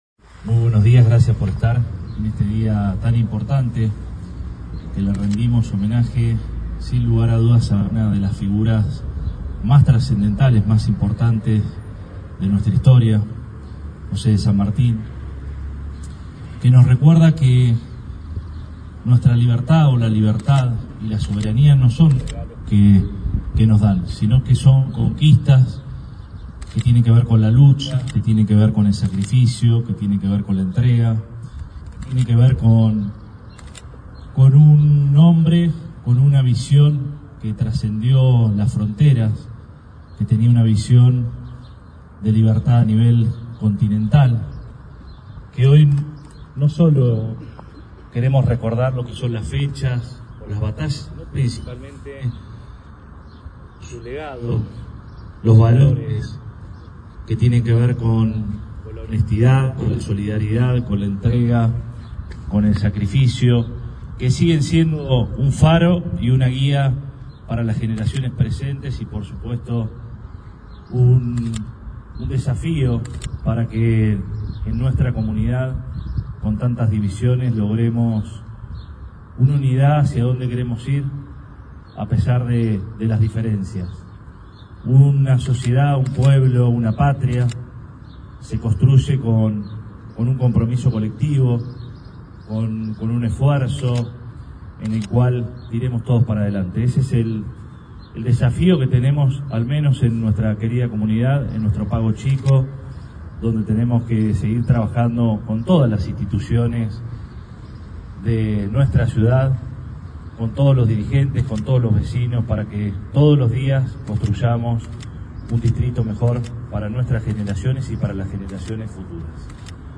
Allí, el Intendente brindó ante chicos y grandes las palabras alusivas a la fecha, honrando el legado de San Martín e instando a construir con compromiso colectivo.
17-08-AUDIO-Arturo-Rojas-Acto-San-Martin.mp3